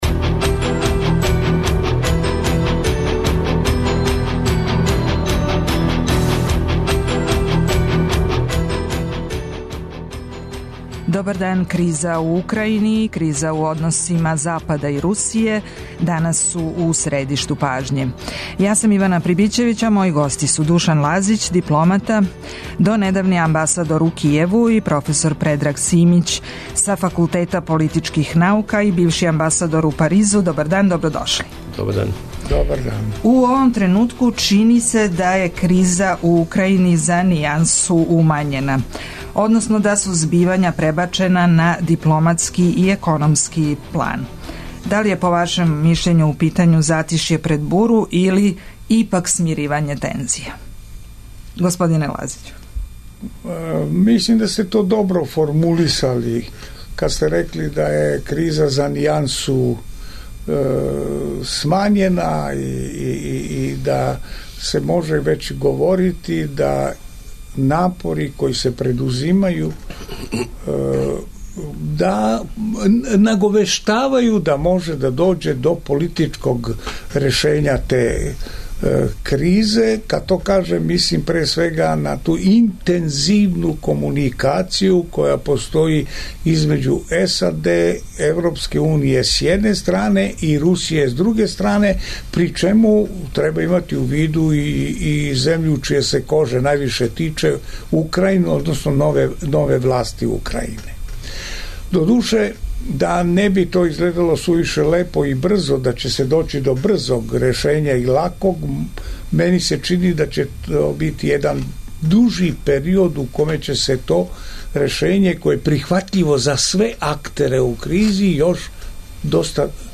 Гости емисије су Душан Лазић, бивши амбасадор Србије у Кијеву и проф Предраг Симић са Факултета политичких наука и бивши амбасадор Србије у Паризу.